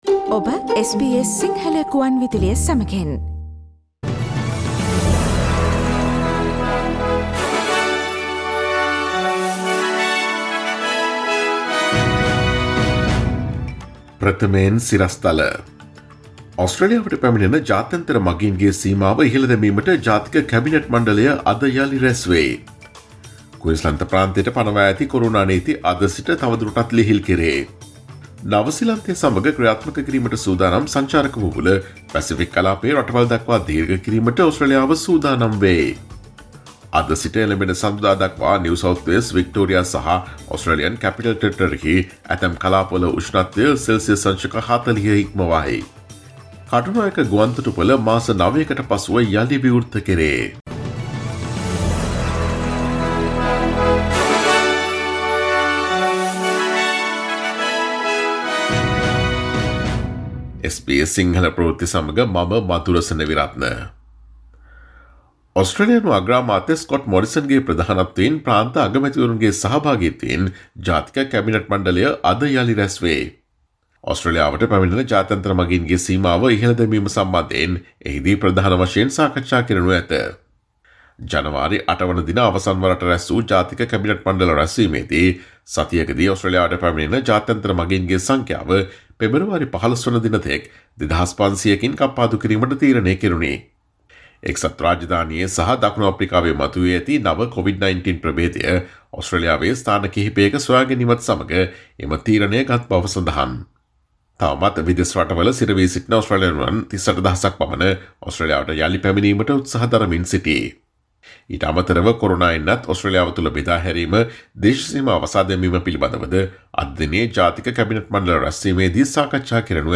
Today’s news bulletin of SBS Sinhala radio – Friday 22 January 2021